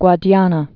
(gwä-dyänə, -thnä)